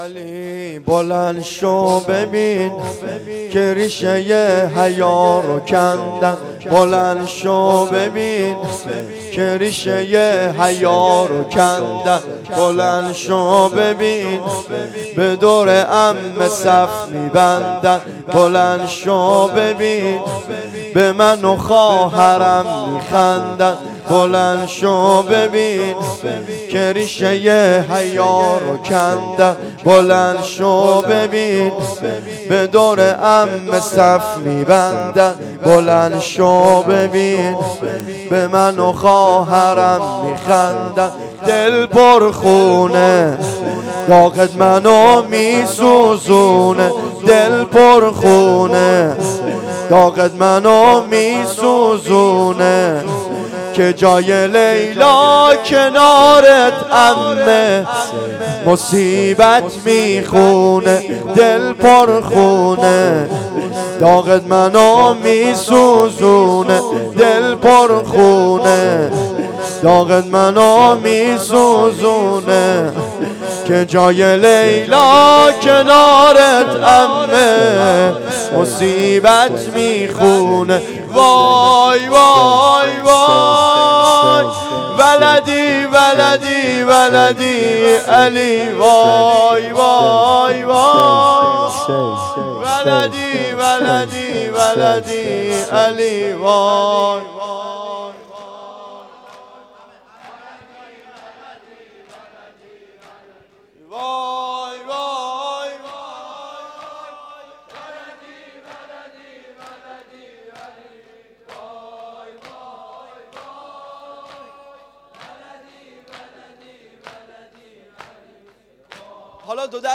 شب هشتم ماه محرم